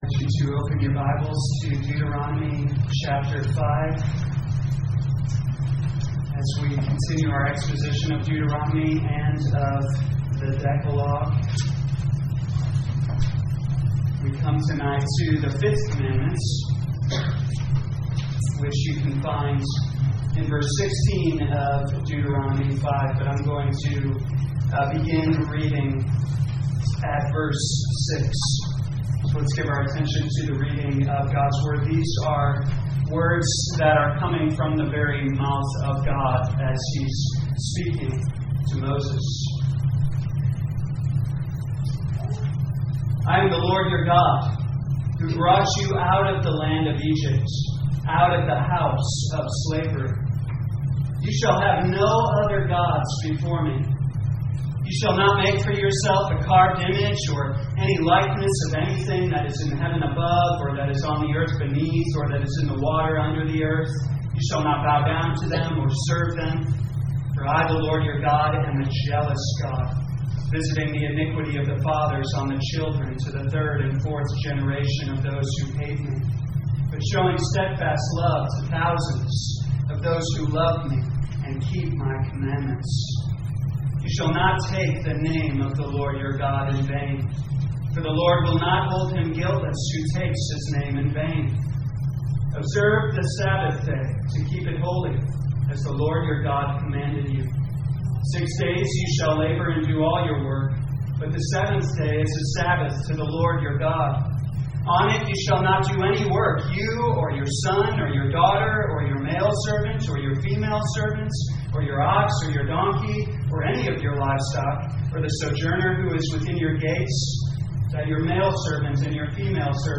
2022 Deuteronomy The Law Evening Service Download
You are free to download this sermon for personal use or share this page to Social Media. Authority Is A Weighty Thing Scripture: Deuteronomy 5:16